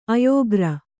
♪ ayōgra